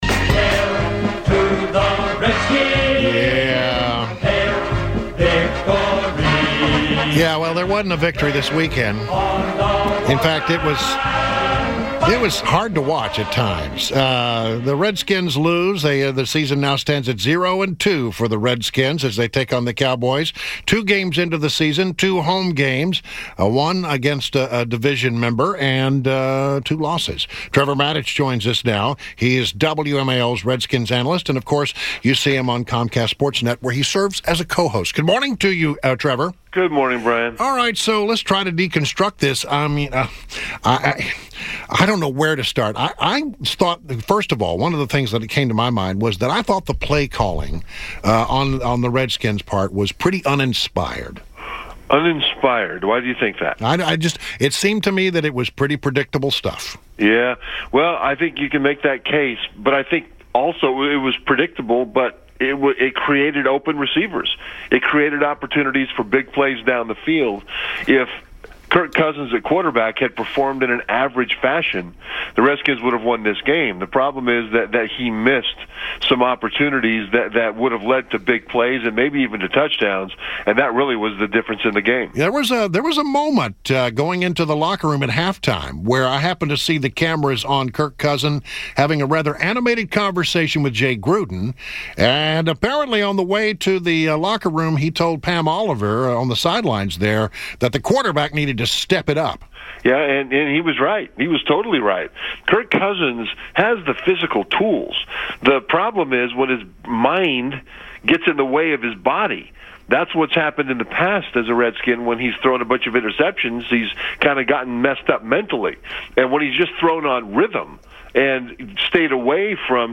WMAL Interview - TREVOR MATICH - 09.19.16
WMAL's Redskins analyst Trevor Matich recapped the Skins game